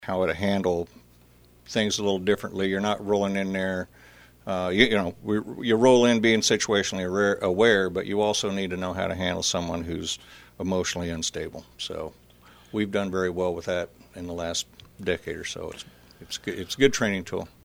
Emporia Police Chief Ed Owens, on the most recent installment of KVOE’s Monthly Q&A segment, says anytime an officer is injured or killed in the line of duty, it impacts all law enforcement agencies, including Emporia.